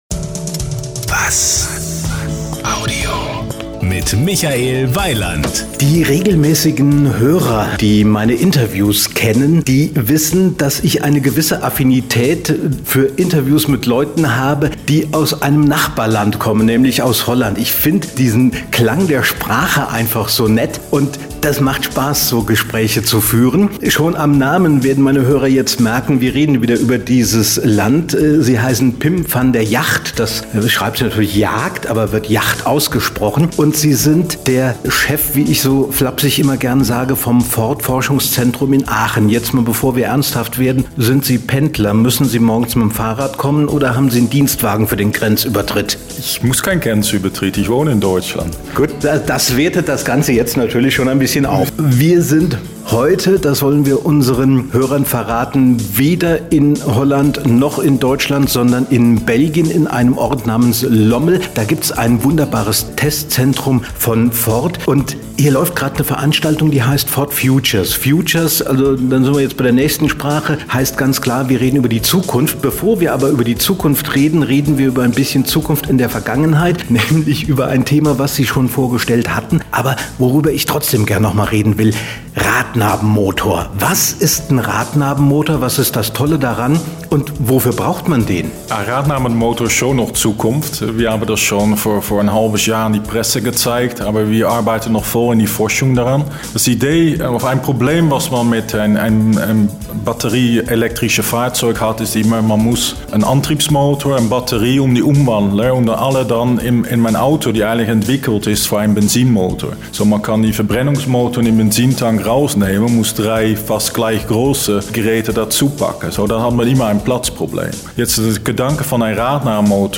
Das komplette Interview hat eine Länge: 22:04 min